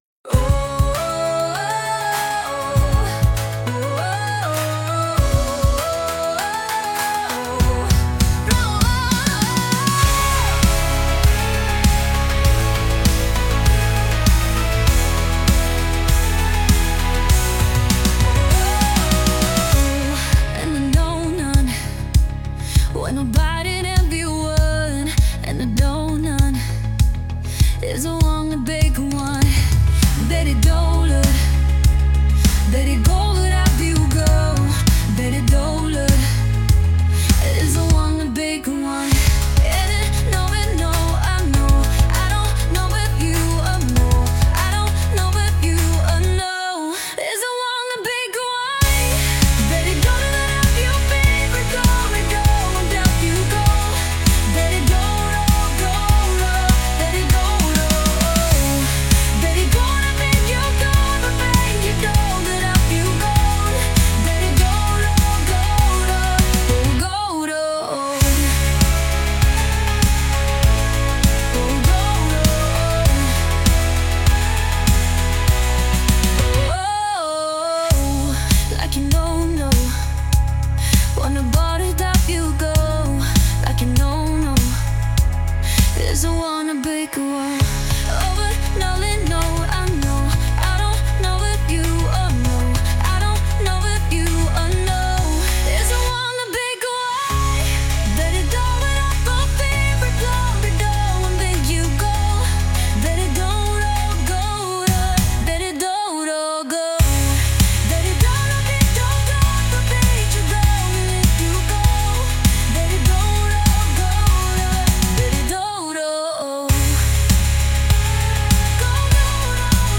Lyrics: instrumental